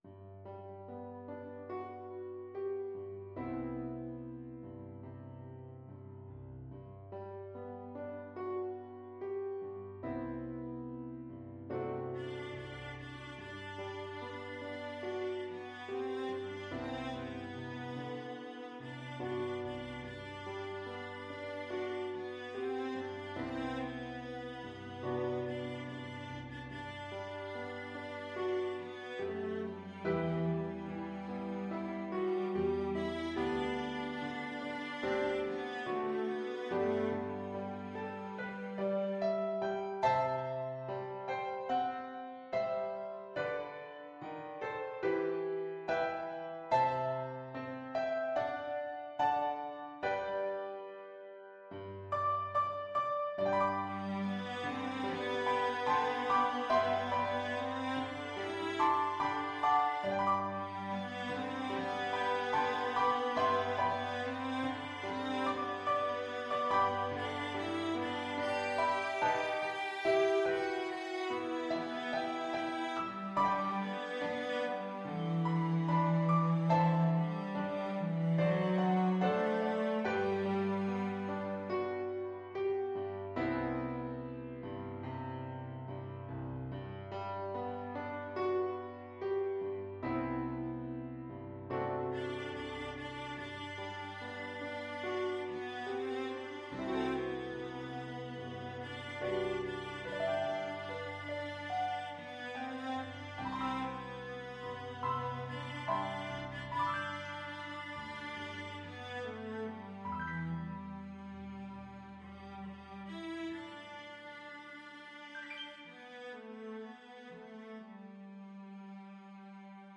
4/4 (View more 4/4 Music)
Molto espressivo =c.72
Classical (View more Classical Cello Music)
Welsh